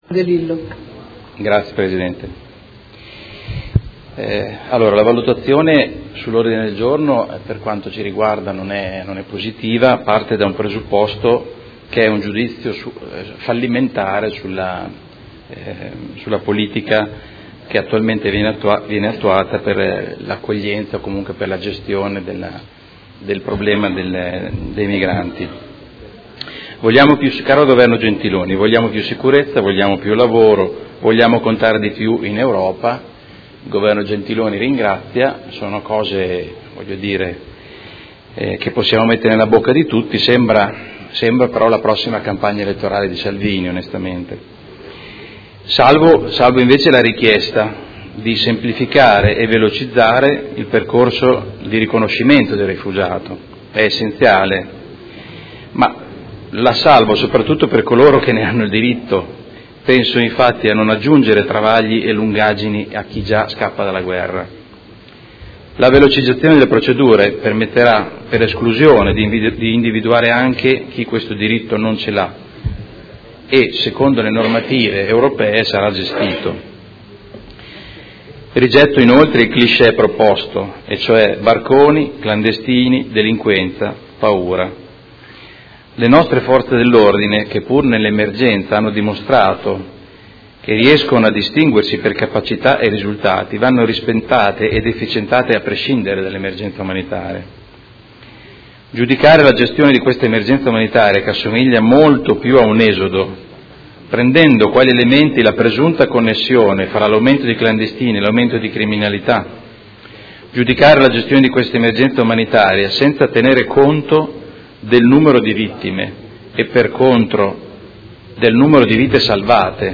Seduta del 16/02/2017. Dibattito su Mozione presentata dal Gruppo Forza Italia avente per oggetto: Le nostre proposte per l’emergenza immigrazione e per la sicurezza